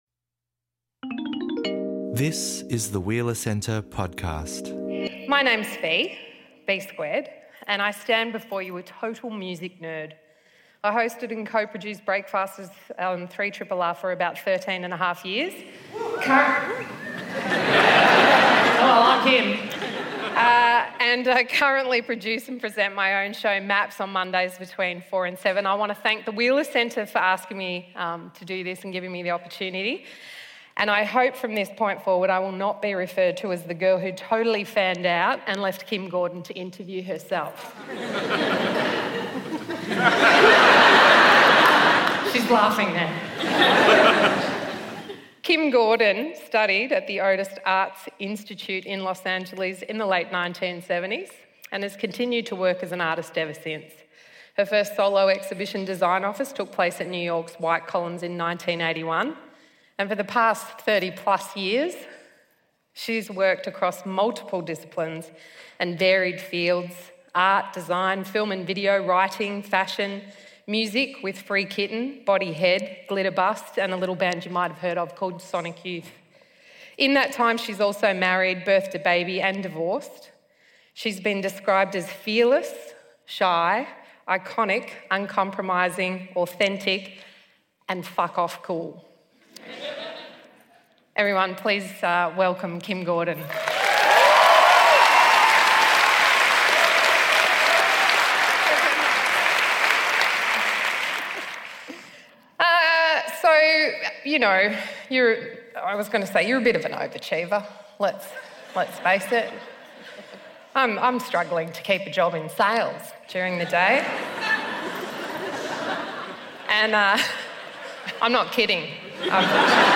for a conversation recorded live at the Astor Theatre